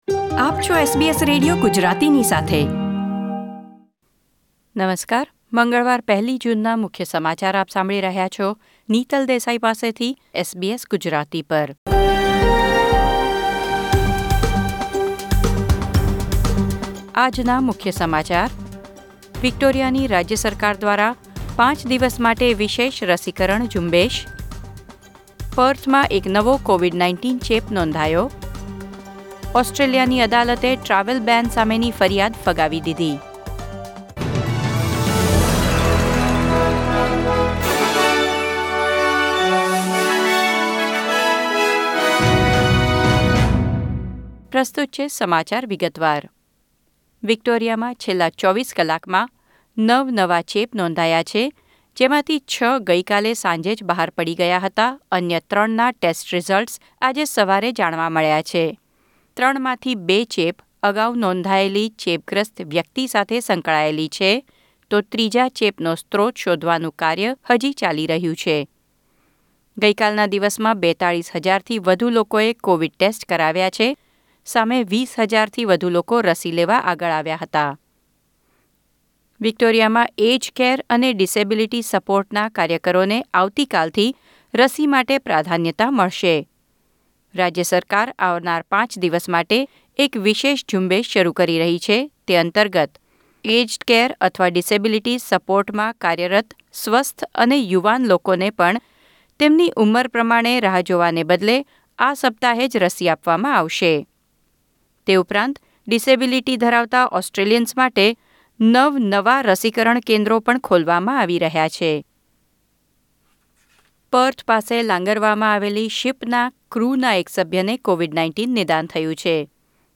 SBS Gujarati News Bulletin 1 June 2021